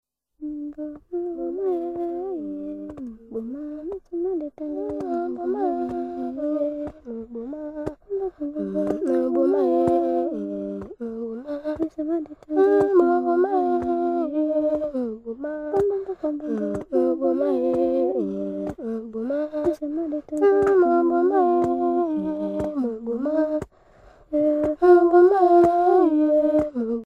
diaphonie-jodel
enfantine : berceuse